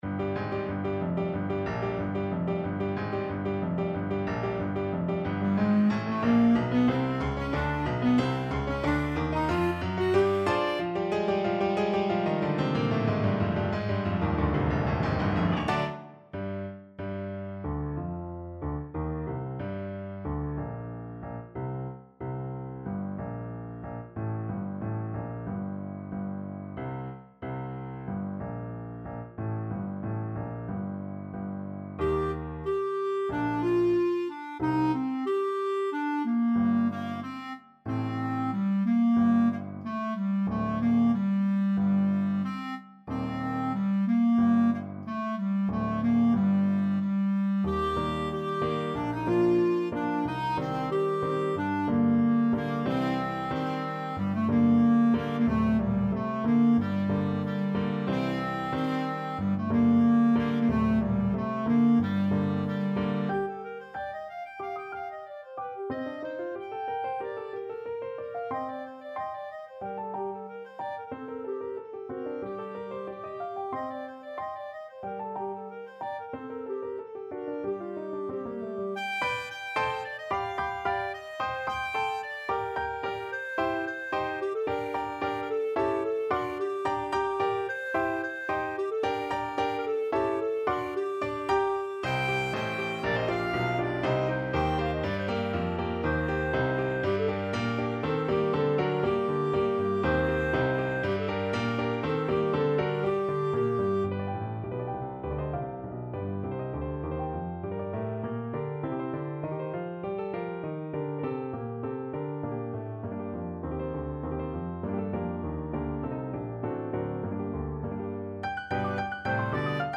Allegro =92 (View more music marked Allegro)
2/4 (View more 2/4 Music)
Classical (View more Classical Clarinet Music)